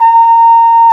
Index of /90_sSampleCDs/Roland L-CD702/VOL-2/BRS_Cornet/BRS_Cornet 2